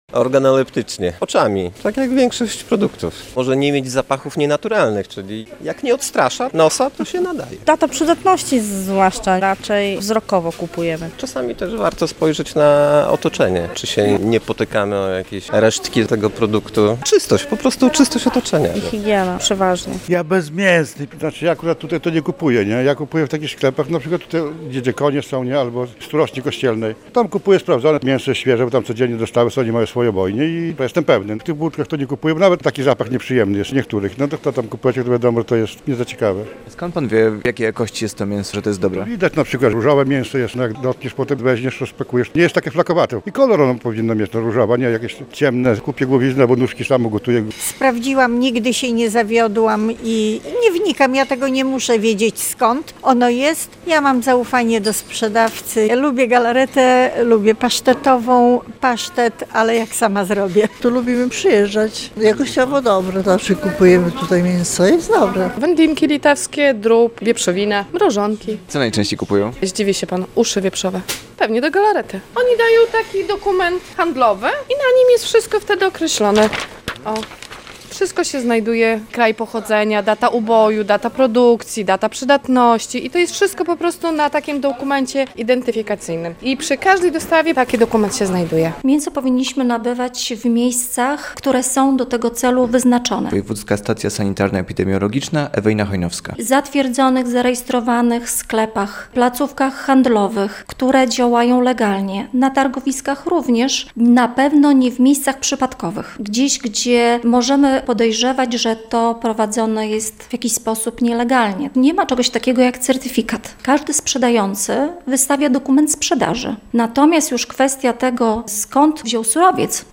Zapytaliśmy białostoczan, czy zwracają uwagę na mięso, które kupują.